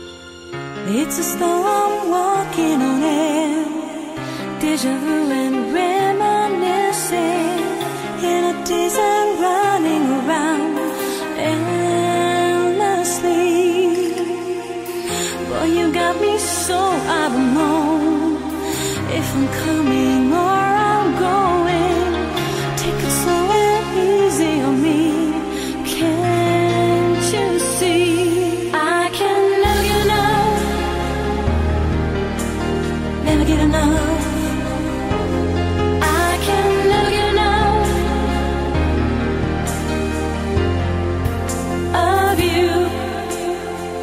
• Качество: 117, Stereo
deep house
Electronic
спокойные
клавишные
пианино
красивый женский голос